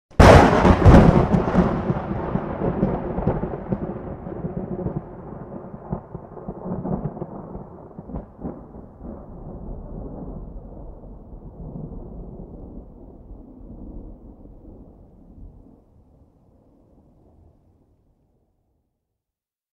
Thunder Clap Loud